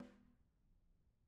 Snare2-HitSN_v1_rr1_Sum.wav